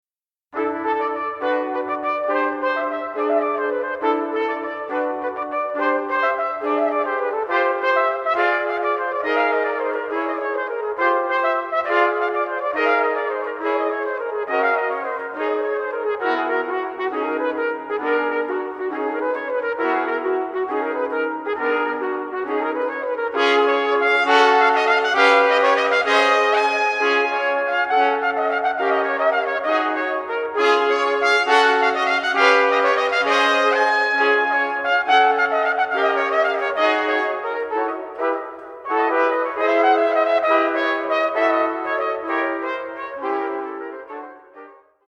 für 4 Trompeten
Trompetenensemble: